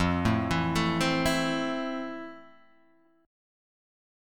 E#maj7 Chord